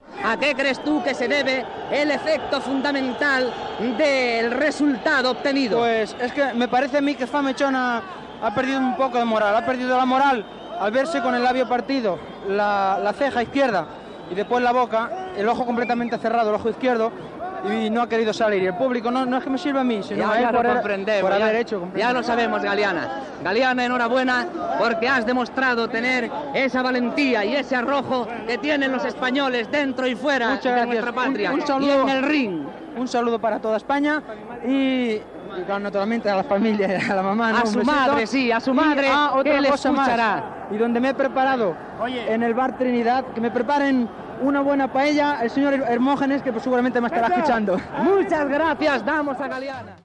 Entrevista a Fred Galiana després del combat.
Esportiu